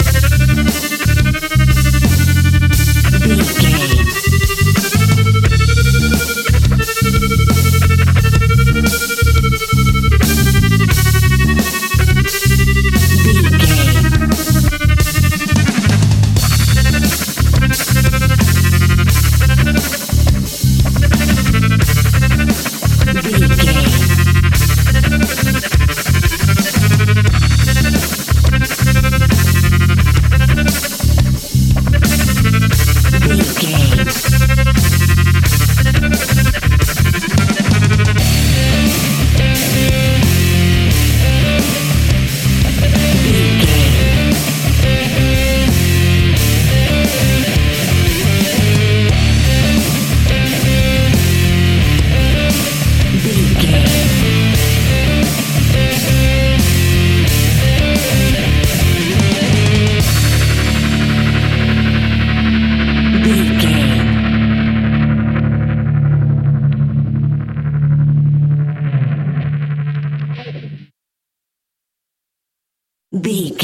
Epic / Action
Fast paced
Aeolian/Minor
hard rock
heavy metal
Rock Bass
heavy drums
distorted guitars
hammond organ